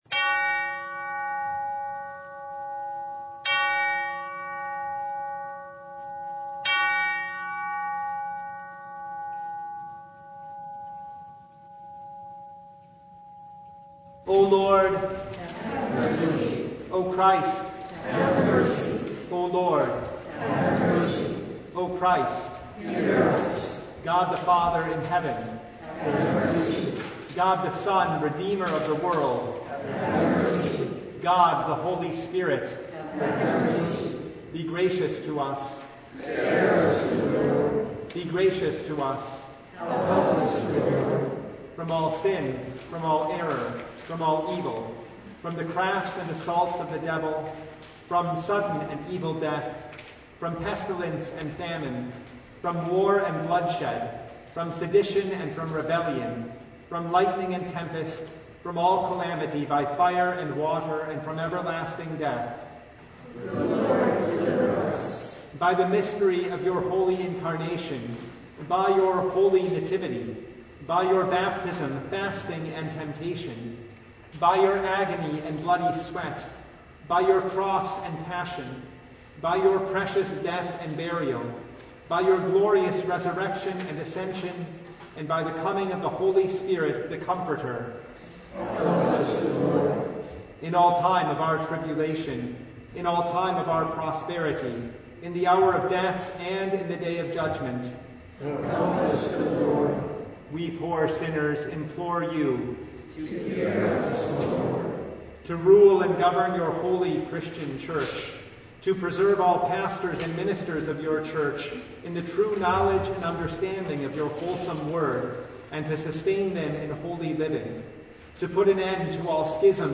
Service Type: Lent Midweek Noon
Full Service